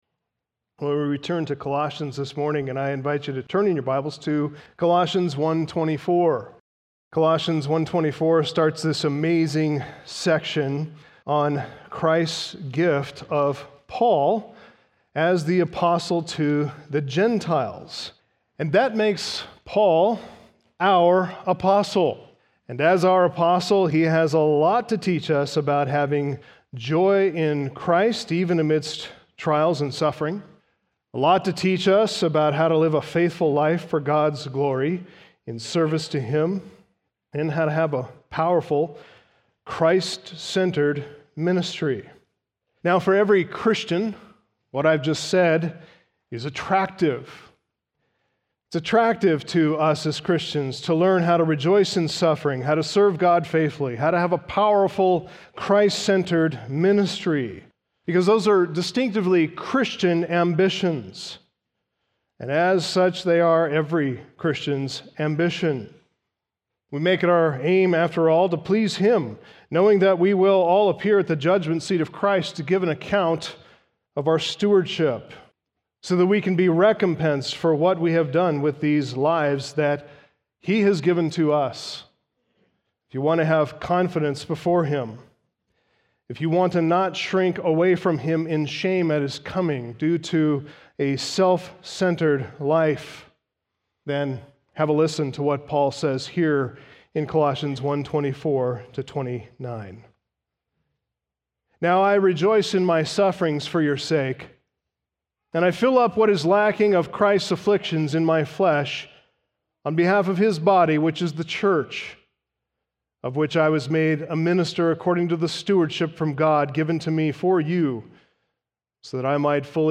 Sermons , Sunday Morning